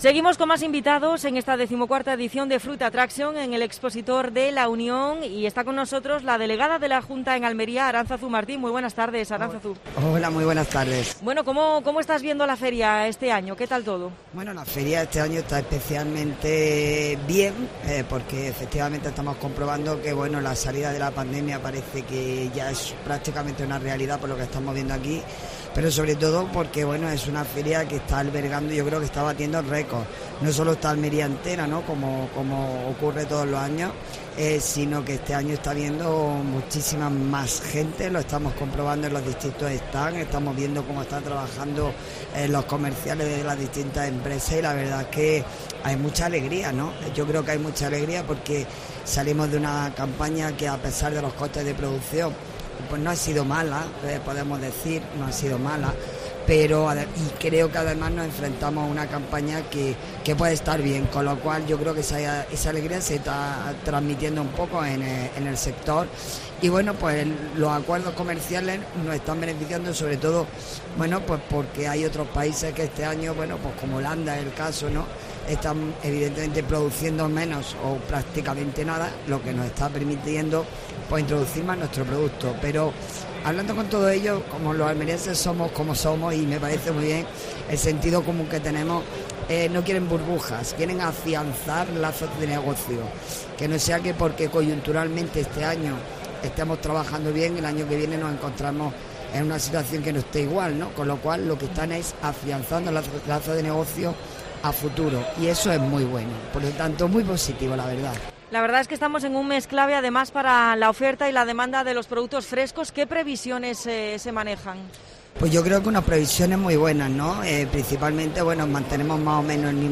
Entrevista desde Fruit Attraction con la delegada de la Junta de Andalucía en Almería.